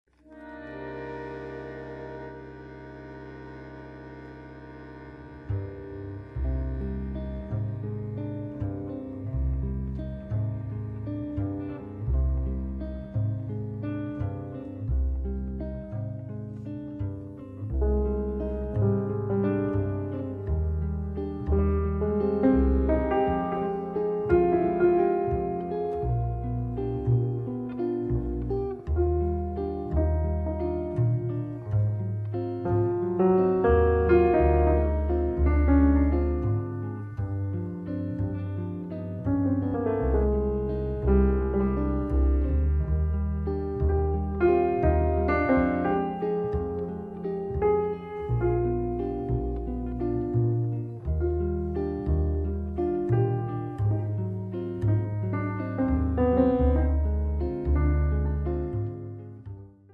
bandoneon
pianoforte
violino
chitarra
basso
Mai freddo, mai alchemico seppur tecnicamente perfetto.